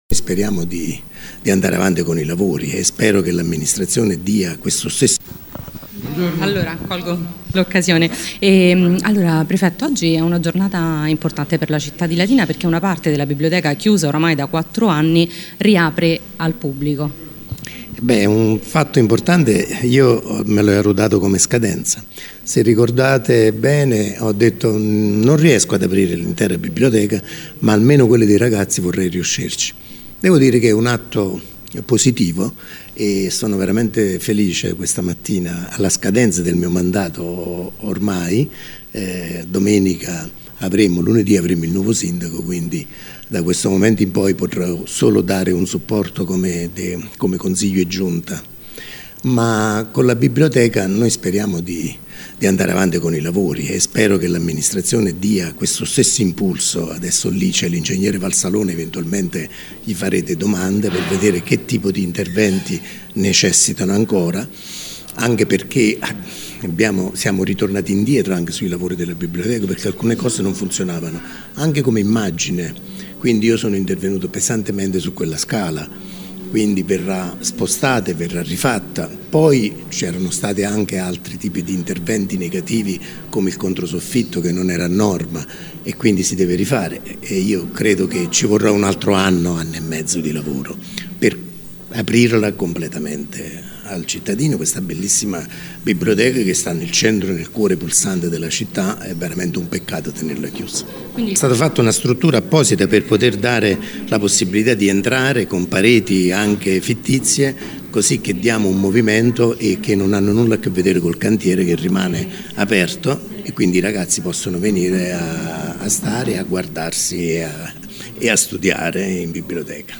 IL COMMISSARIO VALENTE al microfono